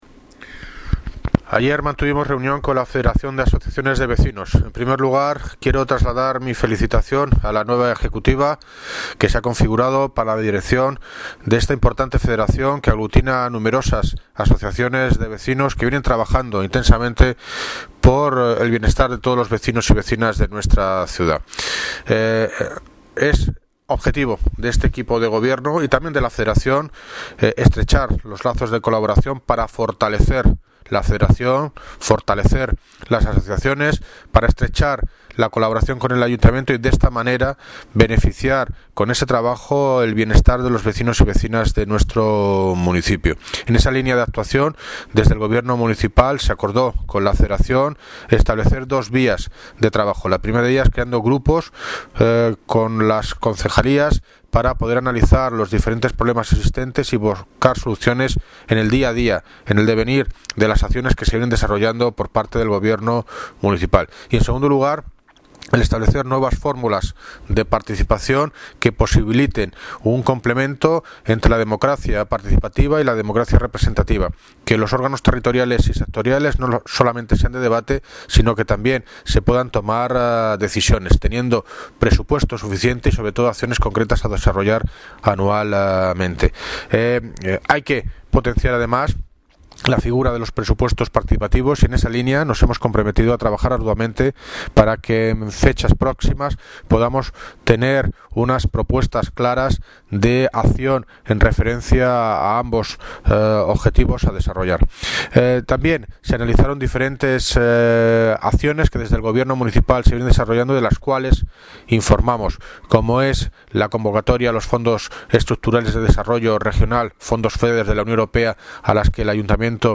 Aidio - David Lucas (Alcalde de Móstoles) sobre reunión Federación de Asociaciones de Vecinos